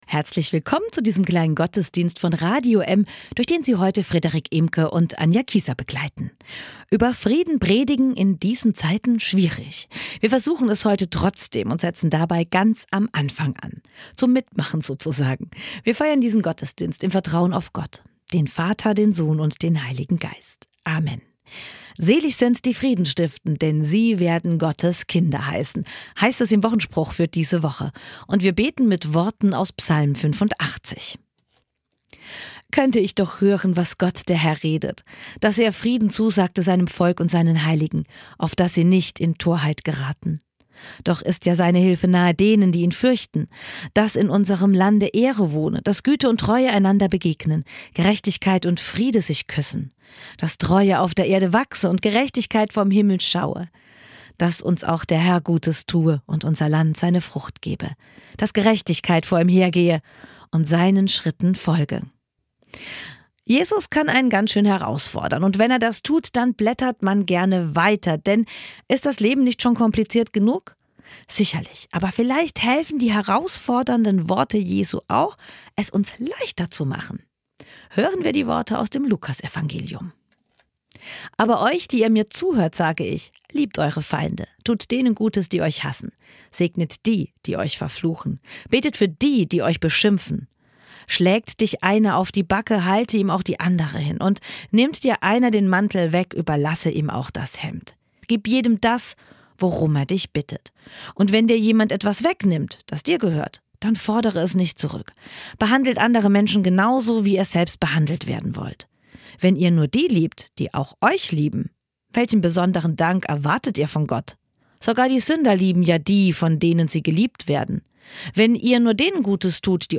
Herzlich willkommen zu diesem kleinen gottesdienst von radio m